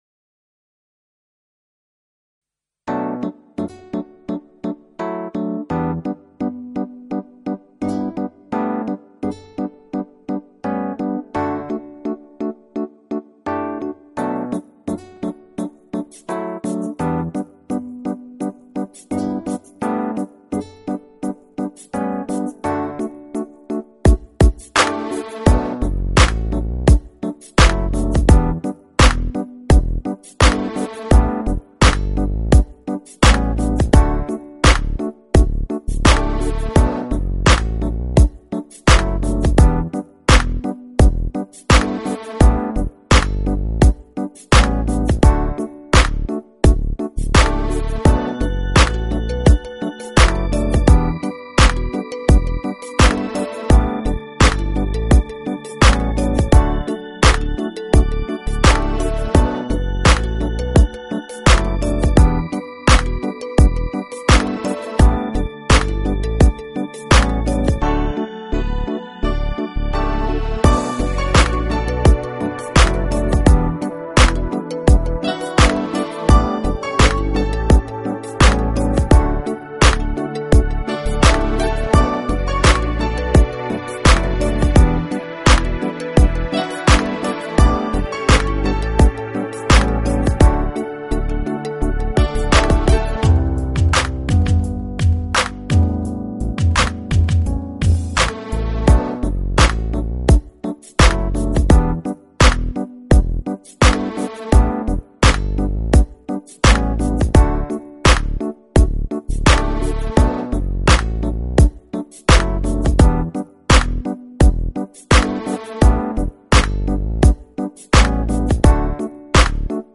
Музыка для подкастов на радио